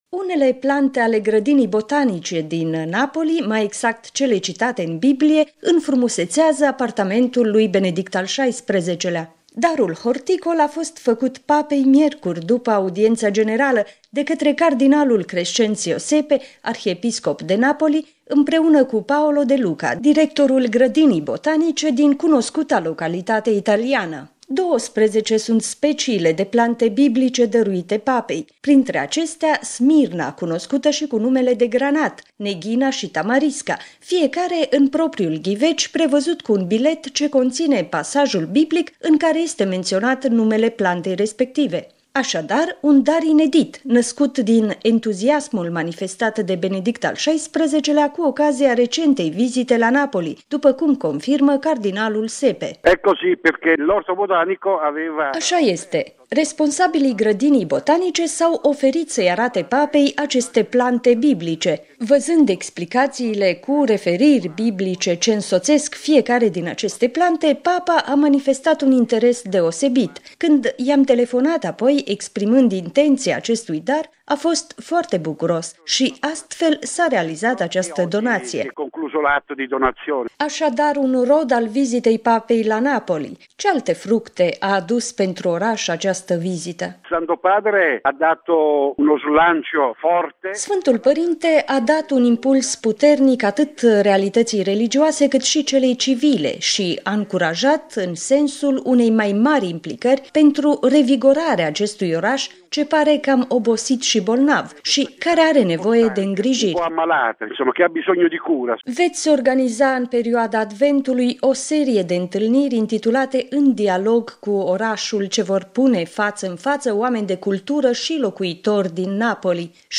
Plante biblice pentru apartamentul Papei, un dar inedit sosit de la Napoli: interviu cu cardinalul Crescenzio Sepe